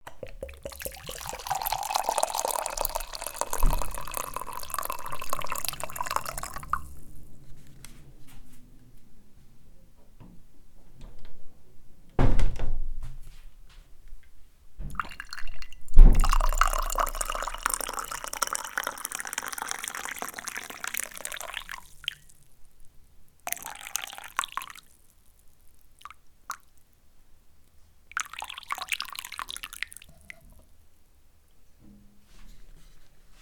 file0236-water
agua bath bubble burp click drain dribble drip sound effect free sound royalty free Nature